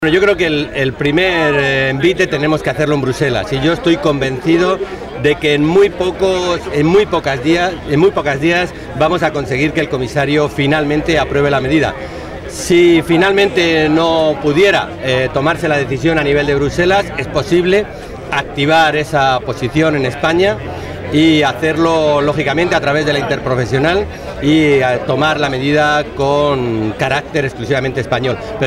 El dirigente socialista hacía estas declaraciones minutos antes de que comenzara la concentración convocada en Toledo por organizaciones agrarias para pedir a la Unión Europea la puesta en marcha del almacenamiento privado de aceite de oliva.
Cortes de audio de la rueda de prensa